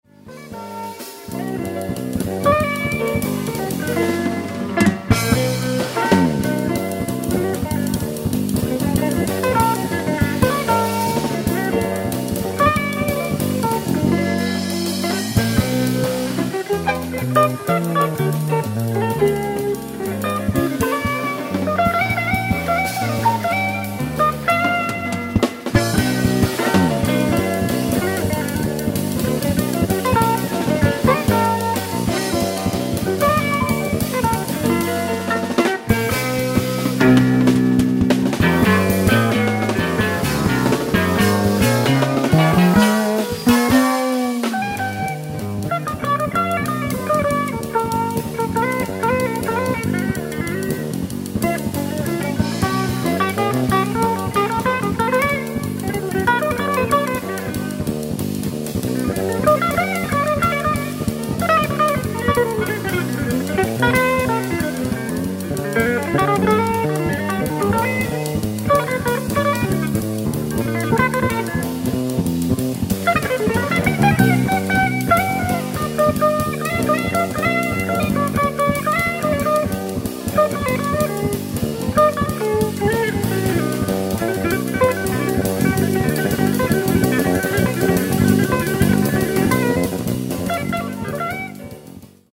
ライブ・アット・エパランジュ、スイス 03/09/1978
※試聴用に実際より音質を落としています。